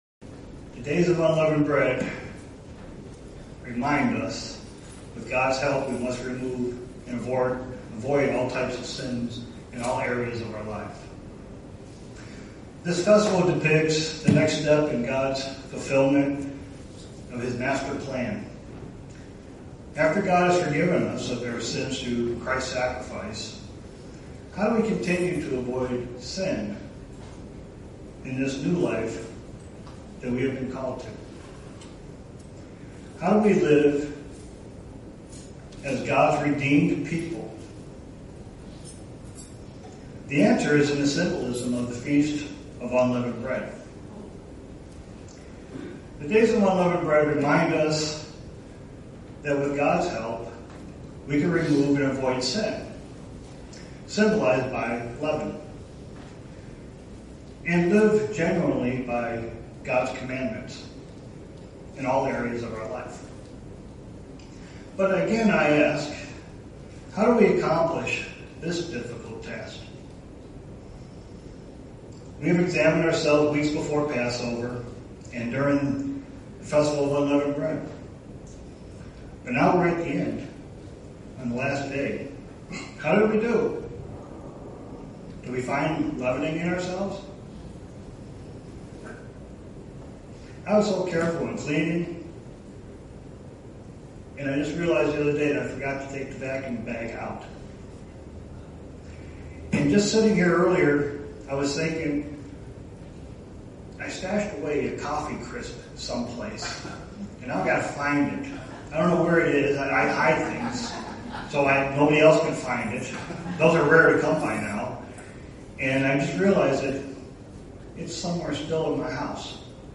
Print Days of Unleavened Bread a time to come out of sin and start a new life. sermon Studying the bible?